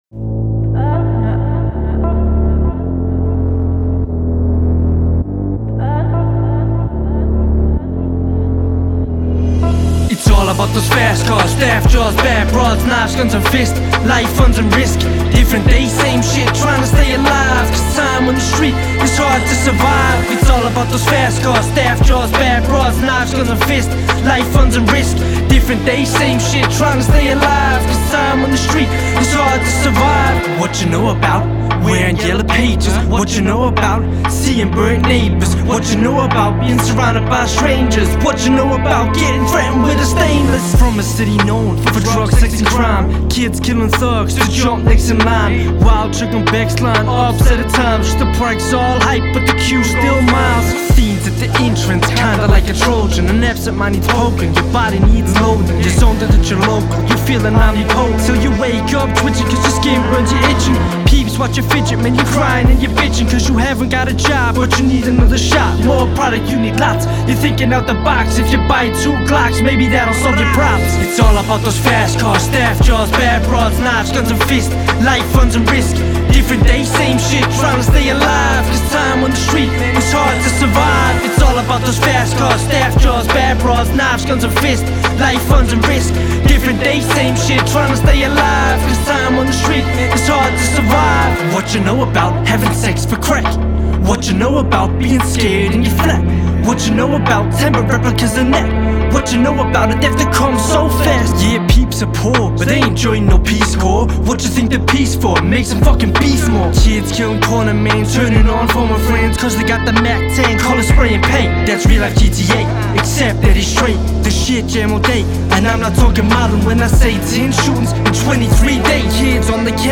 (Rap)
Hmmm ist das schweinelaut oder isses einfach noch zu früh am morgen? Ansonsten klingt es professionell, der Mix ist etwas belegt insgesamt, da könnte man noch ein Element in den Höhen pushen, Hihat zum Beispiel.